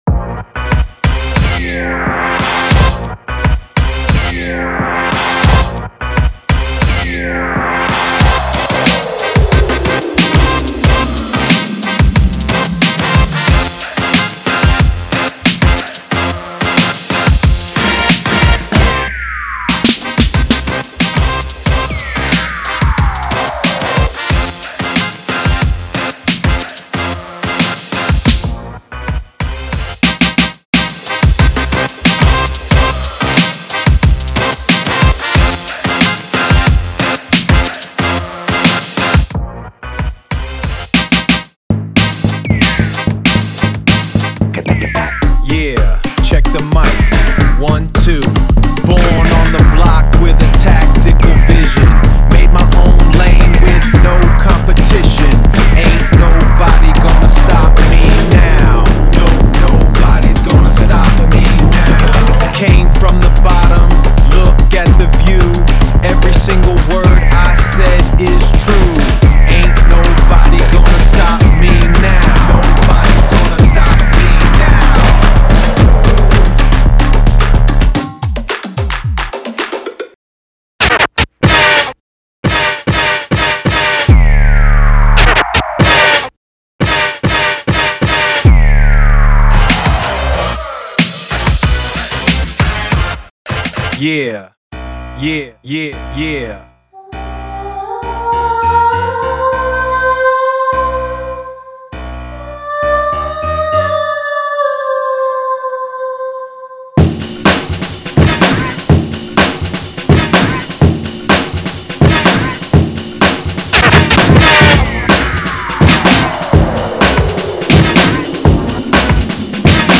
y como no algo de scrathing.